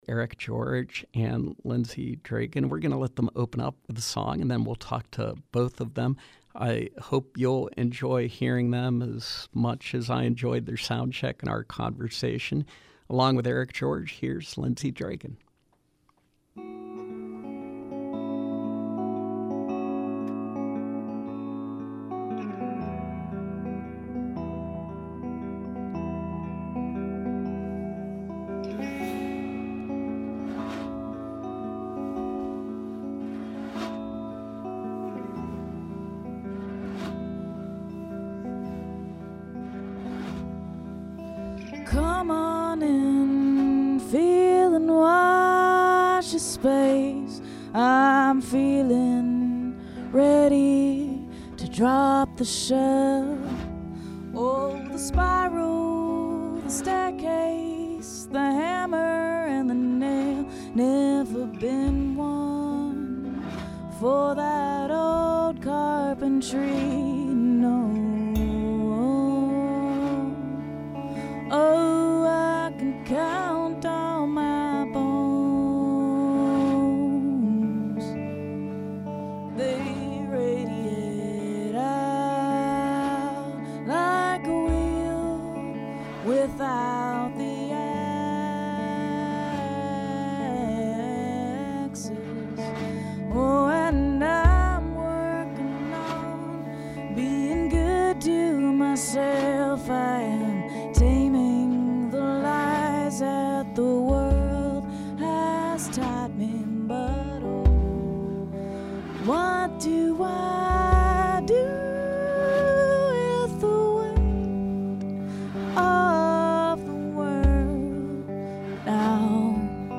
singer-songwriter
percussionist